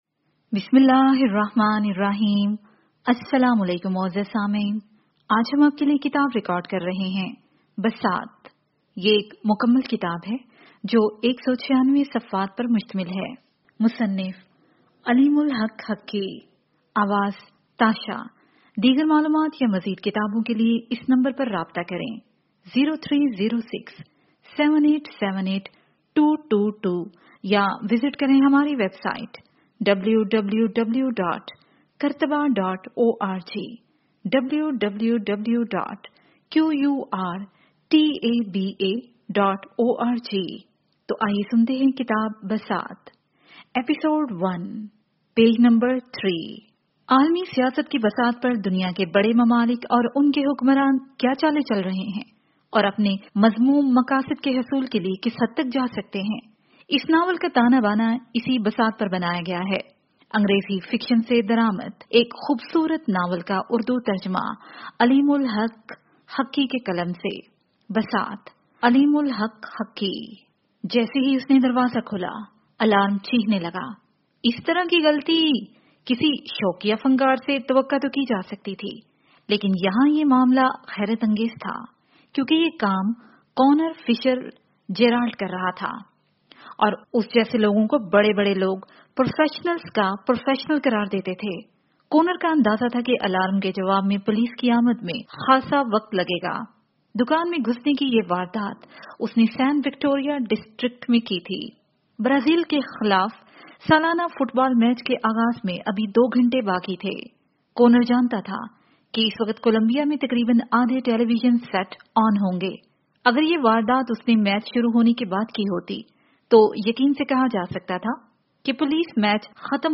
This audio book bisat is written by a famous author aleem ul haq haqi and it is listed under novel category.